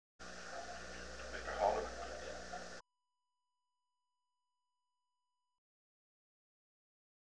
Location: Camp David Hard Wire
The President talked with the Camp David operator.